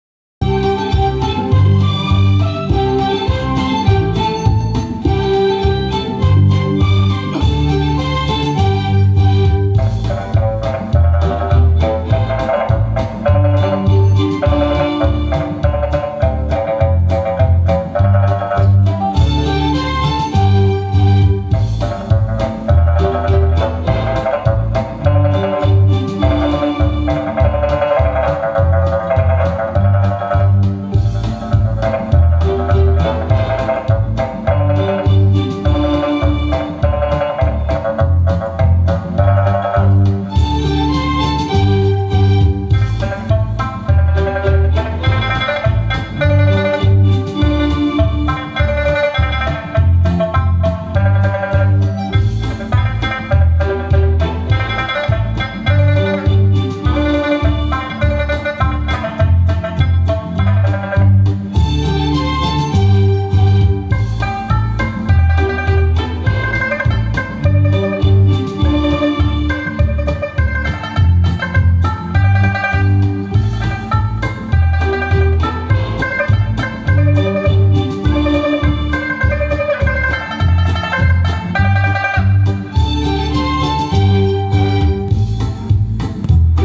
3个月前 0 我用电吹管吹的，哈哈。
美茵m1000三弦音色吹六口茶片段.mp3